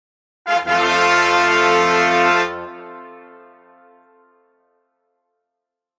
Download Ta Da sound effect for free.
Ta Da